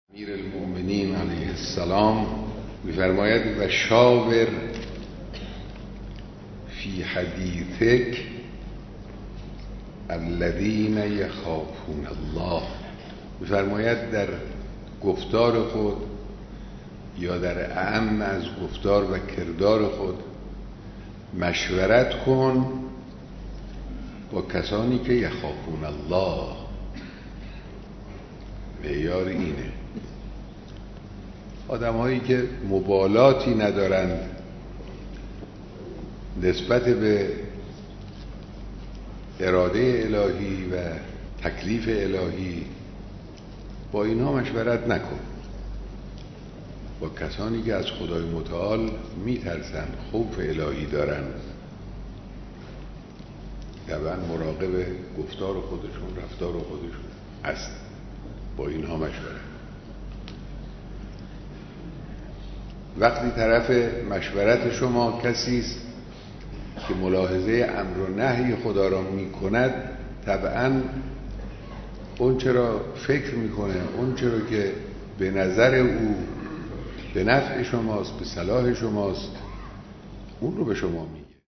شرح حدیث اخلاقی توسط رهبر - از چه کسانی مشورت بگیریم(فیلم، صوت، متن)